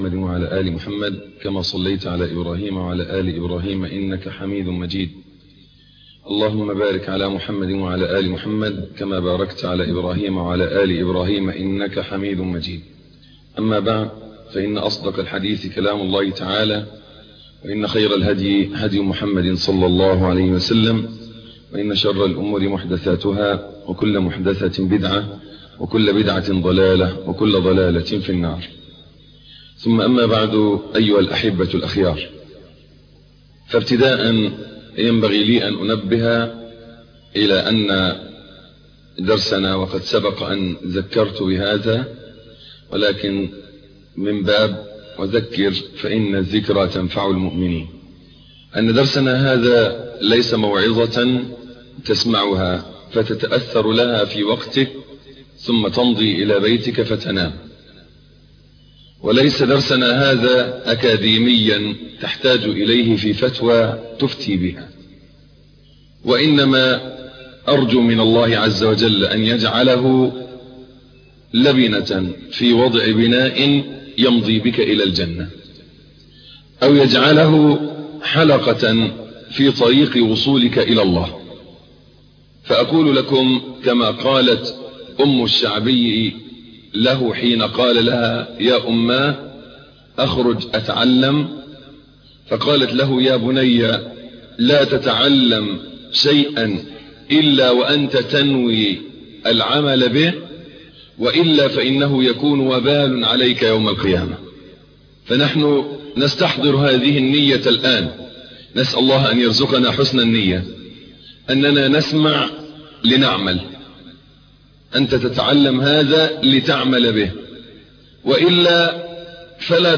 الدرس السادس - فضيلة الشيخ محمد حسين يعقوب